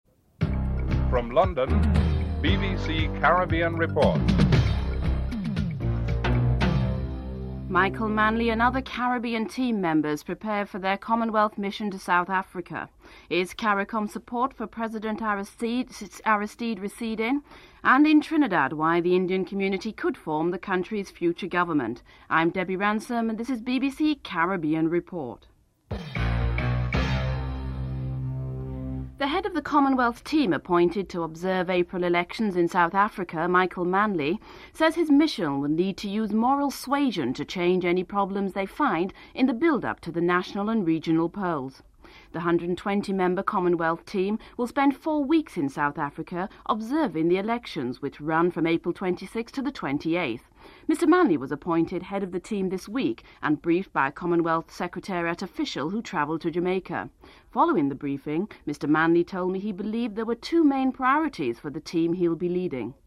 3. Interview with Dudley Thompson, Jamaica’s High Commissioner to Nigeria, who believes the presence of the observers would help to quell any pre-election violence in South Africa (03:11-04:22)